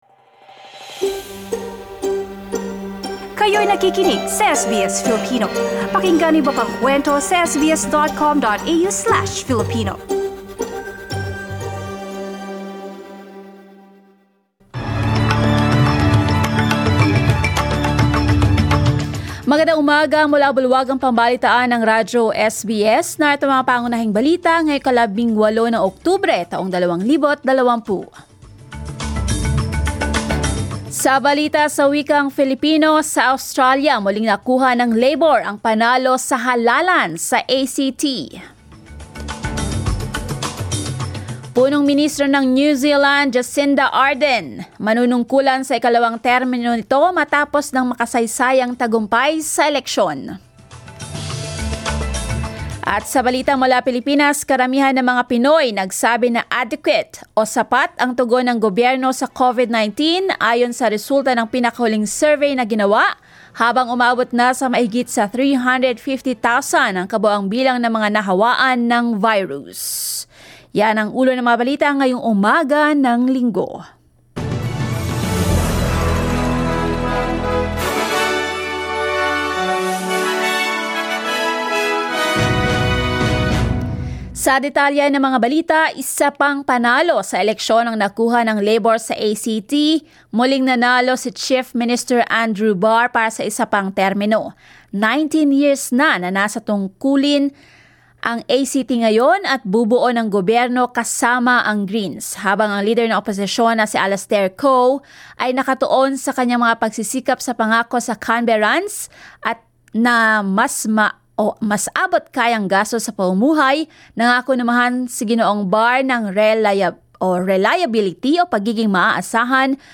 SBS News in Filipino, Sunday 18 October